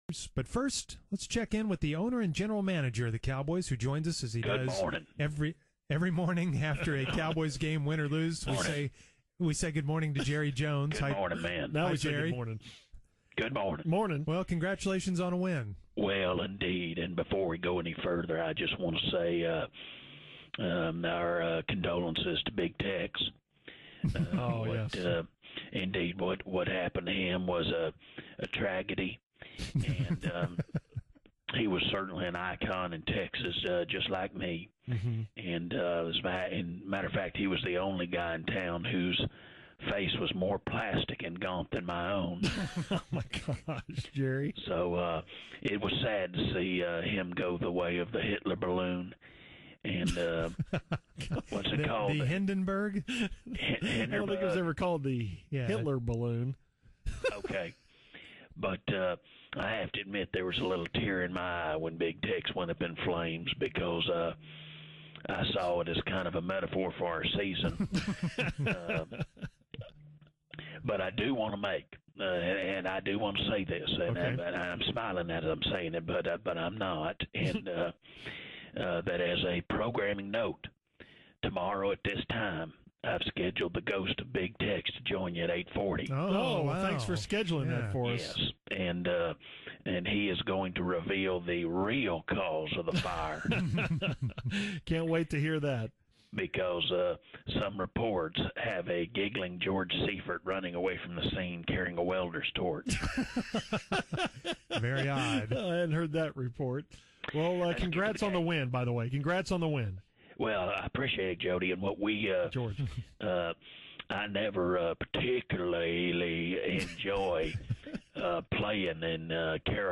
The Musers called the fake Jerry Jones to get his reaction to the big win over the 1-5 Panthers. Problems with Jason Garrett’s offensive coordinator program are revealed, and the fake Garrett stops by for a short, bug-filled cameo.